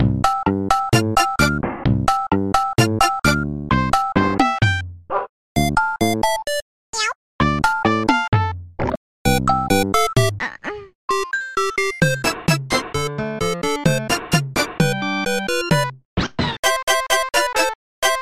Self-recorded